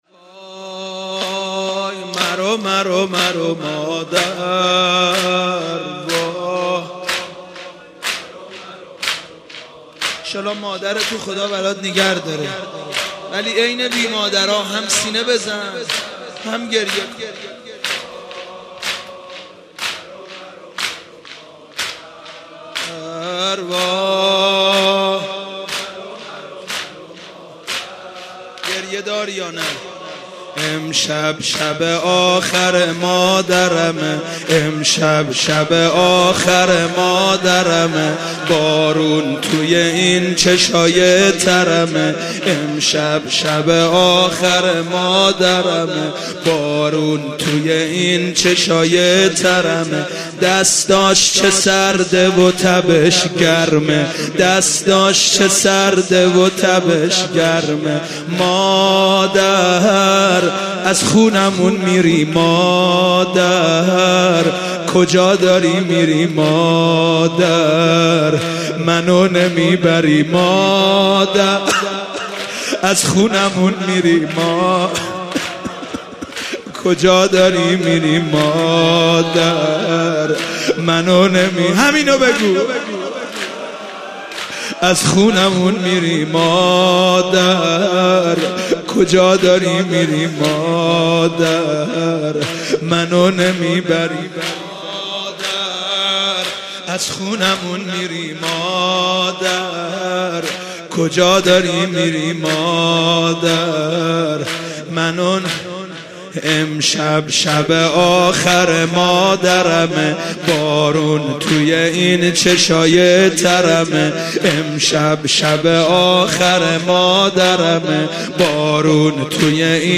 شب هفتم رمضان95، حاج محمدرضا طاهری
زمینه، روضه، مناجات